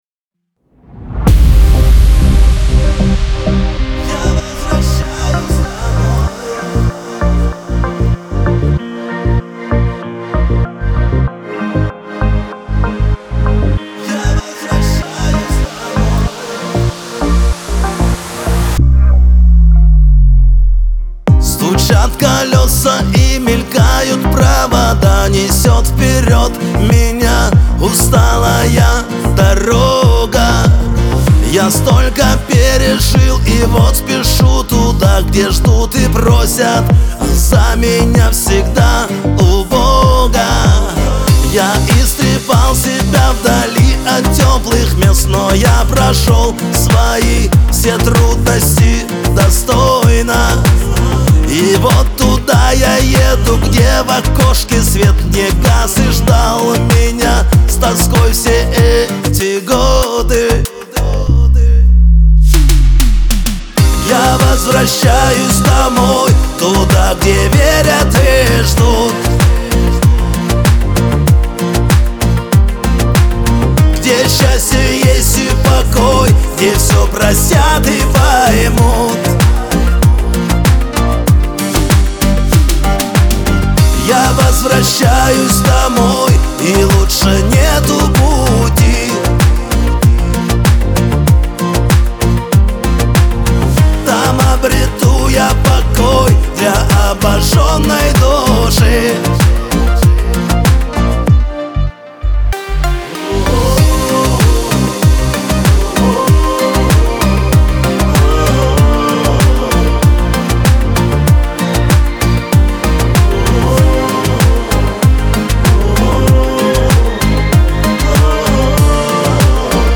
диско
эстрада , pop